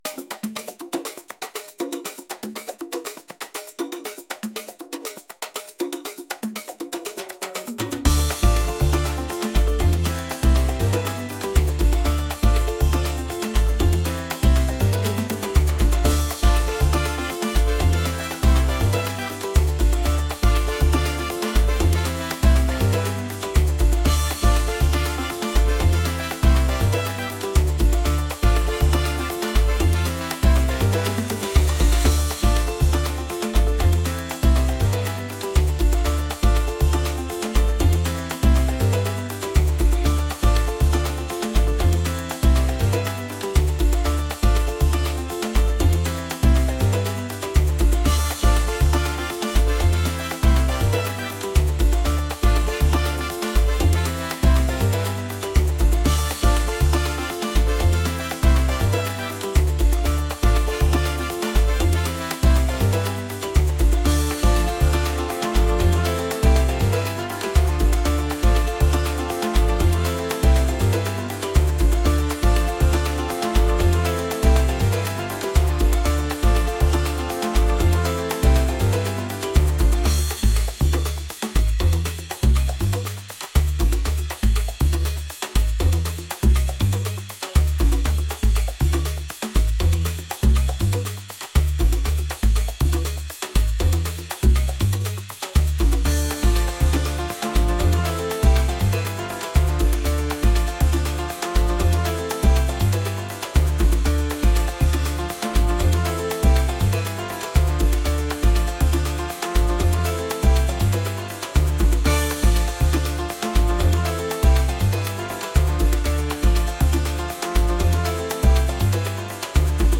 rhythmic | latin